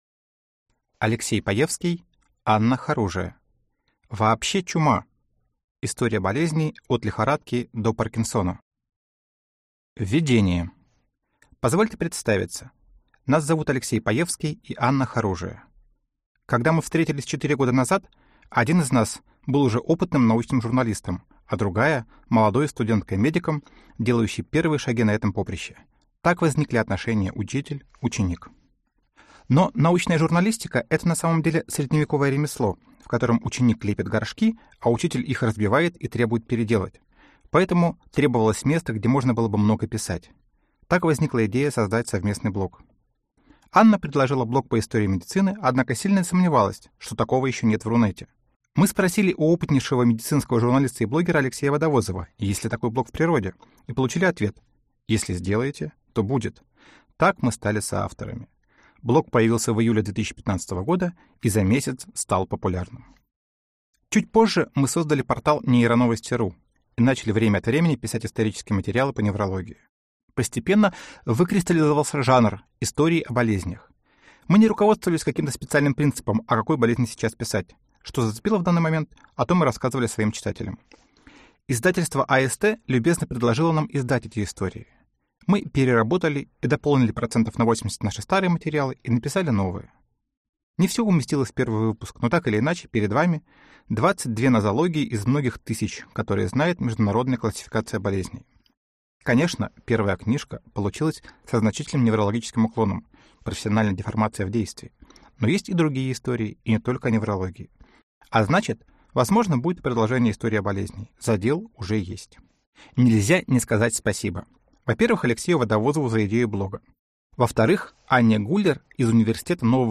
Аудиокнига Вообще ЧУМА! История болезней от лихорадки до Паркинсона | Библиотека аудиокниг